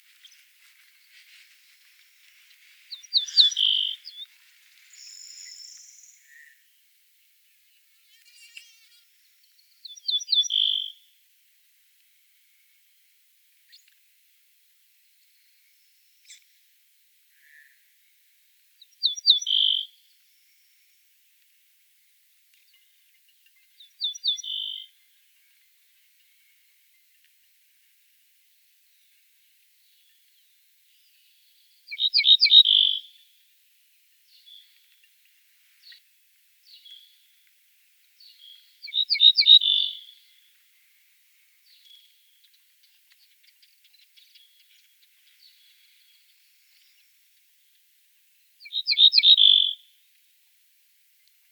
Emberiza hortulana - Ortolan bunting - Ortolano